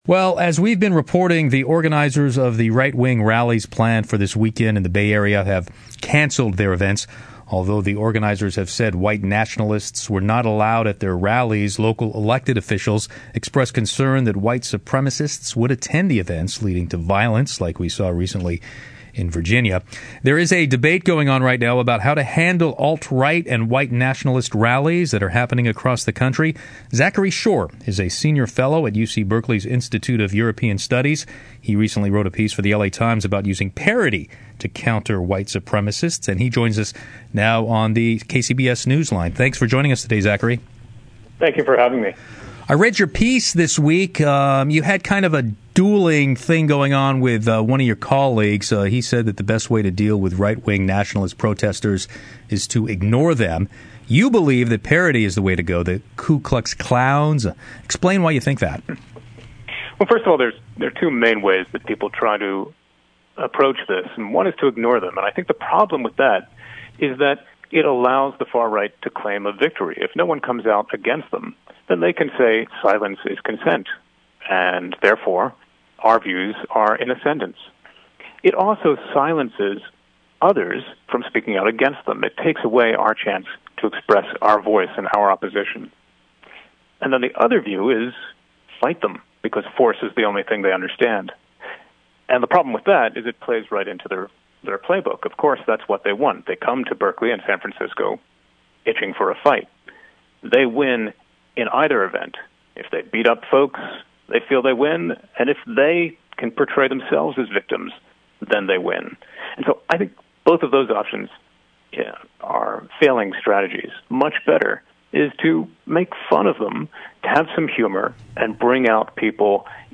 Interview on KCBS Radio, San Francisco, August 24, 2017.